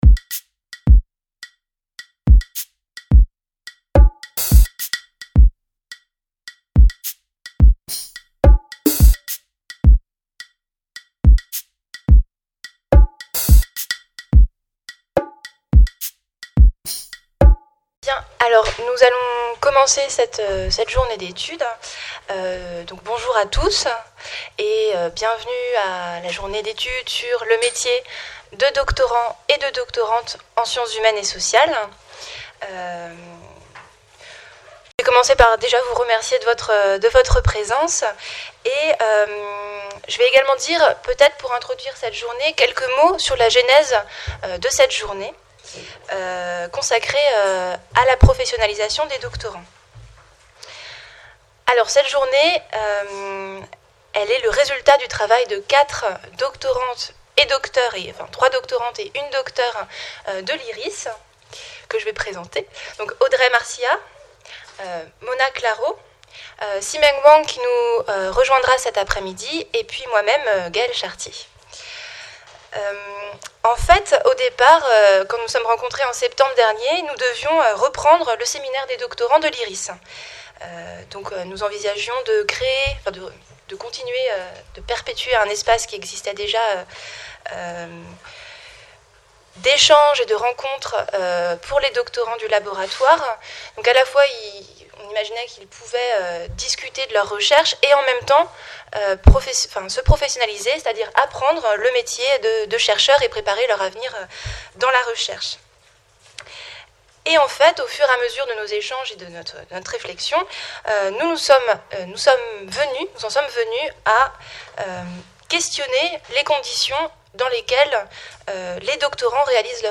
Le métier de doctorant.e en SHS Journée d'études organisée avec le soutien de l'Iris Enregistré le jeudi 25 septembre 2014, à l'EHESS, Salle du Conseil.